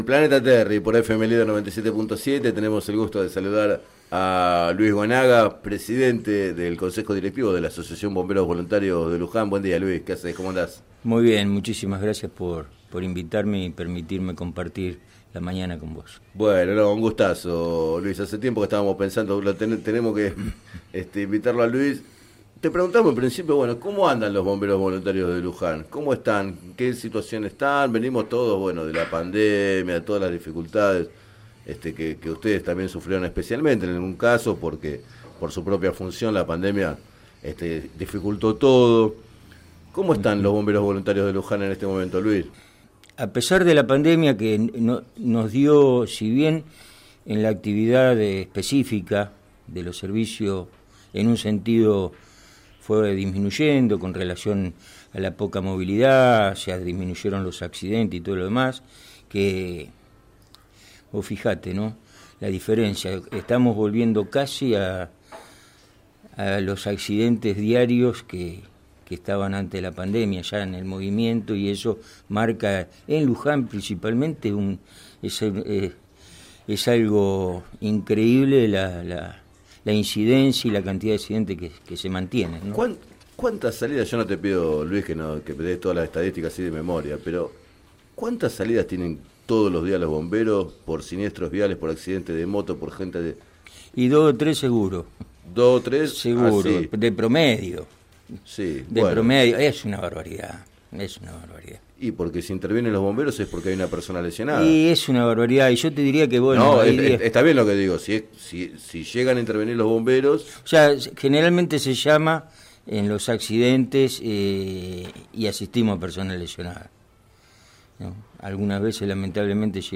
Entrevistado en el programa “Planeta Terri” de FM Líder 97.7